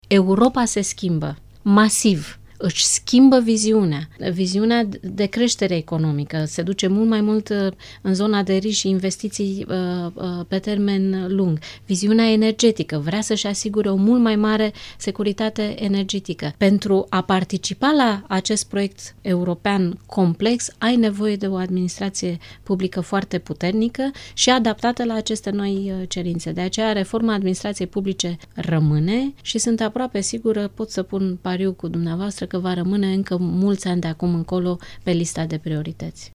Invitată la Interviurile Europa FM, Angela Filote a explicat că funcționarii români au salarii mici și sunt suprasolicitați.